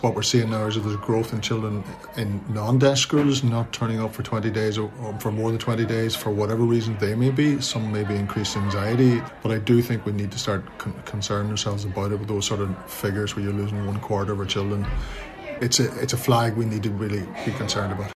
Children’s Ombudsman, Donegal native, Niall Muldoon says the rise in school avoidance is concerning: